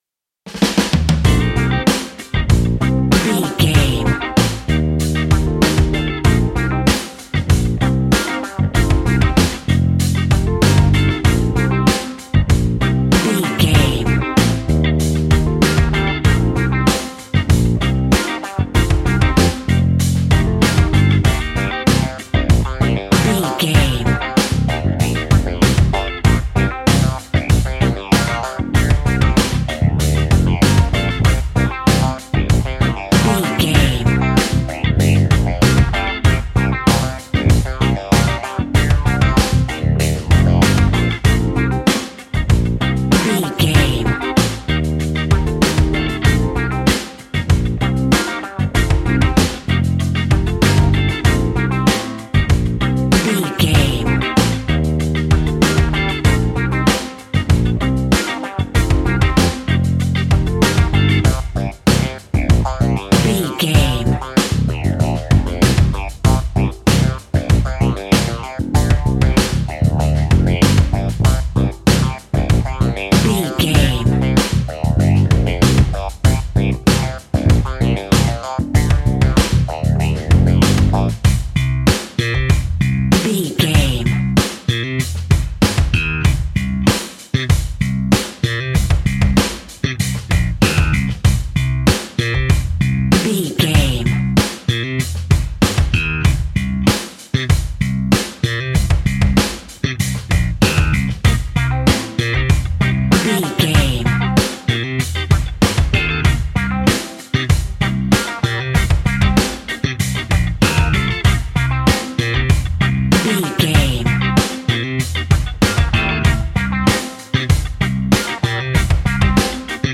Aeolian/Minor
lively
electric guitar
electric organ
drums
bass guitar
saxophone
percussion